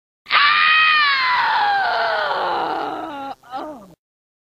Sonya Blade Scream UMK3
sonya-blade-scream-umk3.mp3